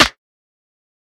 TC2 Snare 23.wav